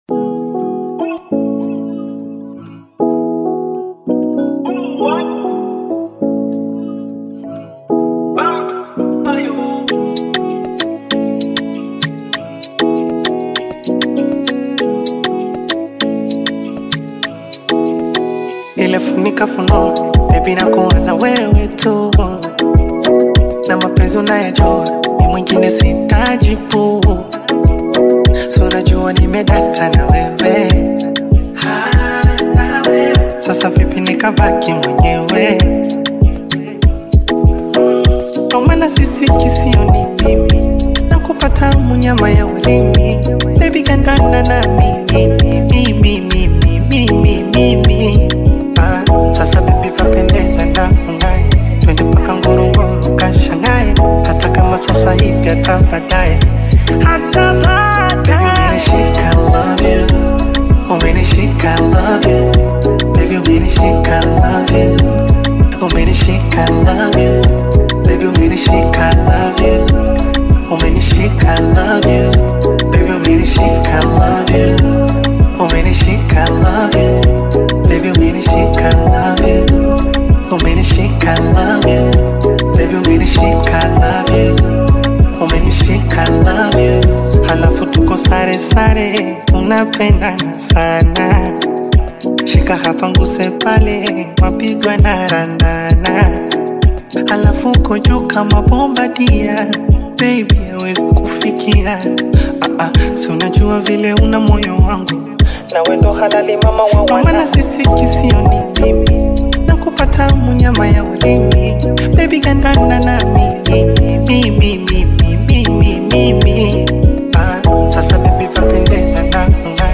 bongo Flavour
Tanzanian singer and songwriter